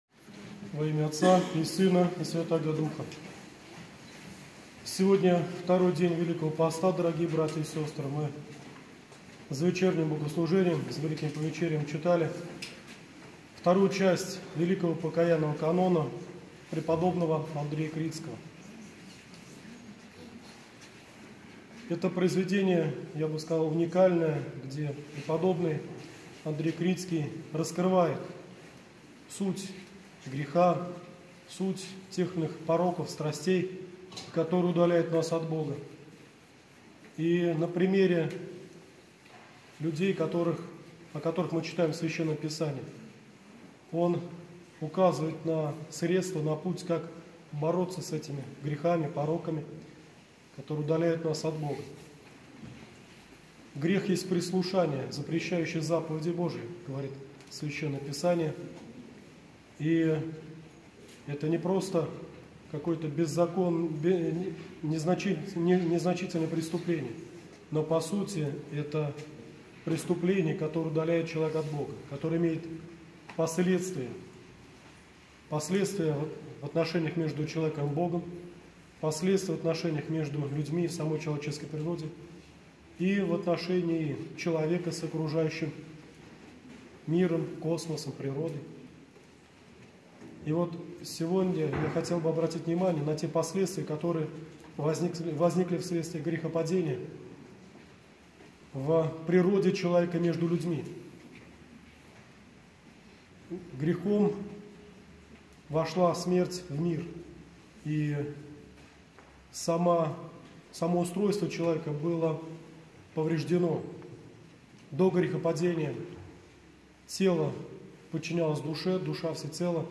Проповедь для прихожан